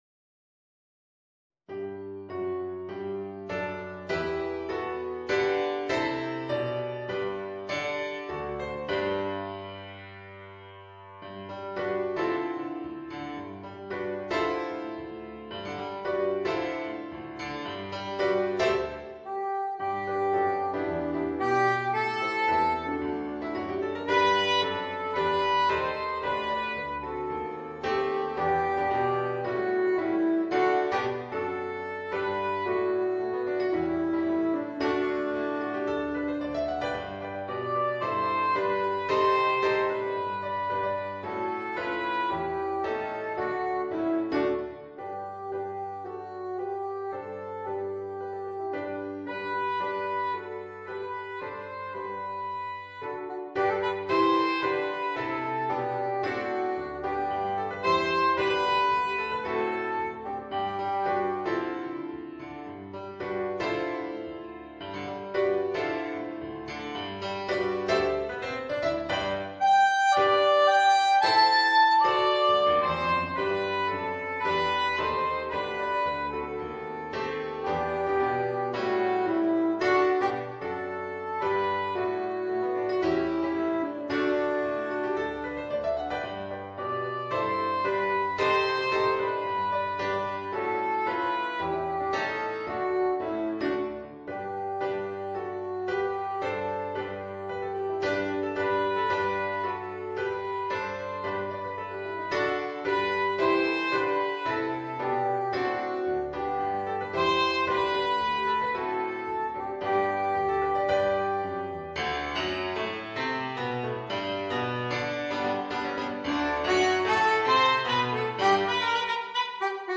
ソプラノサックス+ピアノ